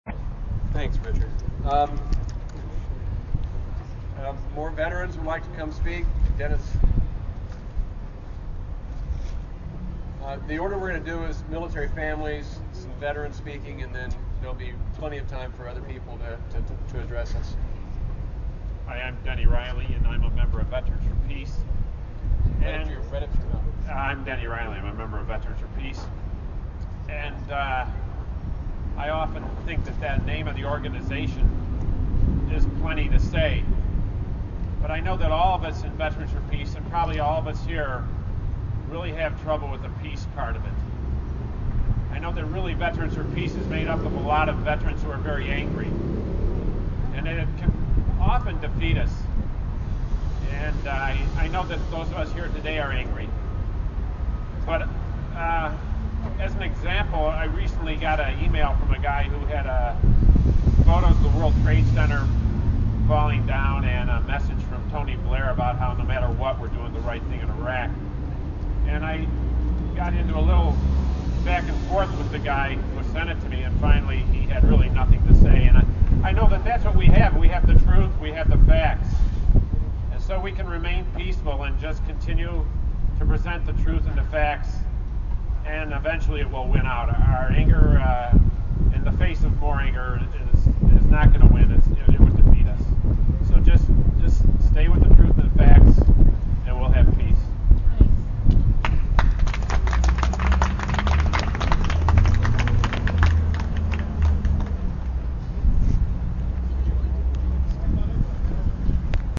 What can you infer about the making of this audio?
Audio From Camp Casey SF Anti-War VIgil